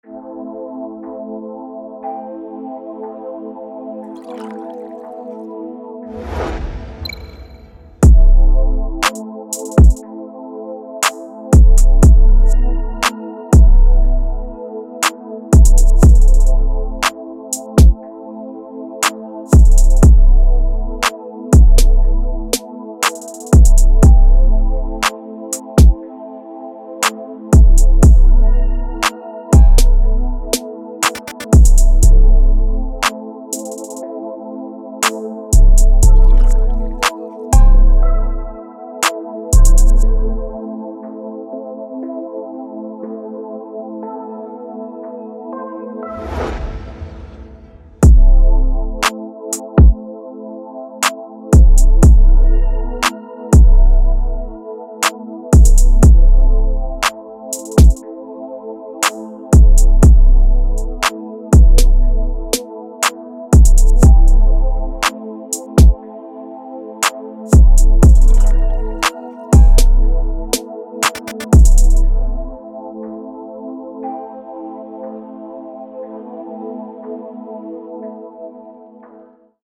Energetic, Positive, Sexy
Acoustic Guitar, Drum, Heavy Bass, Piano, Strings